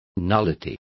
Complete with pronunciation of the translation of nullities.